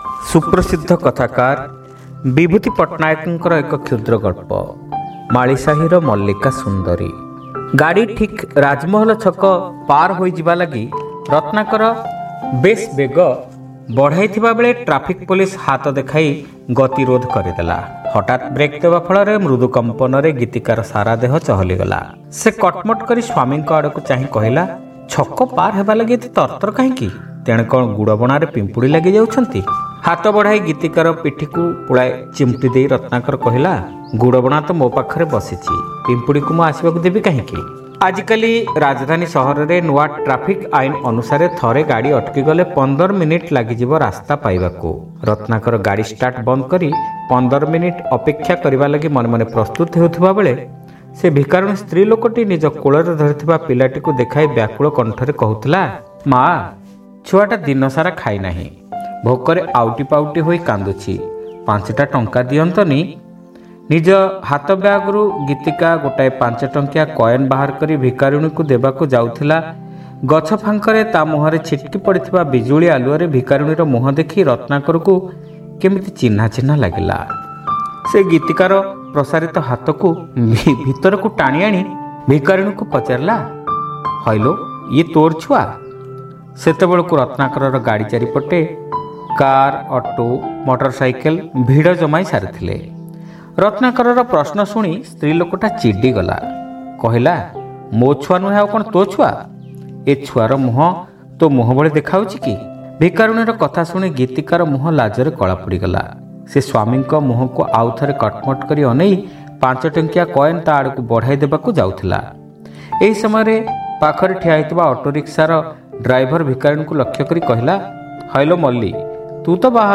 ଶ୍ରାବ୍ୟ ଗଳ୍ପ : ମାଳିସାହିର ମଲ୍ଲିକା ସୁନ୍ଦରୀ